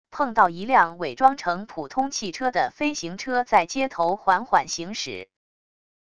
碰到一辆伪装成普通汽车的飞行车在街头缓缓行驶wav音频